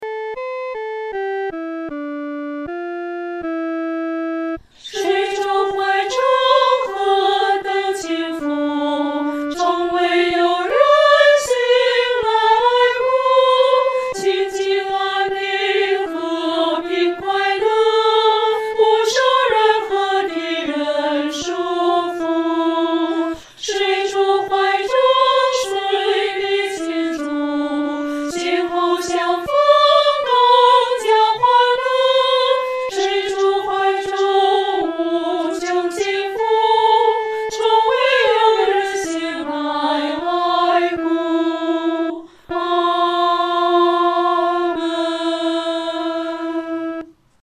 女高
其旋律、和声构成无比宁静的气氛，在丧礼中给人莫大的安慰。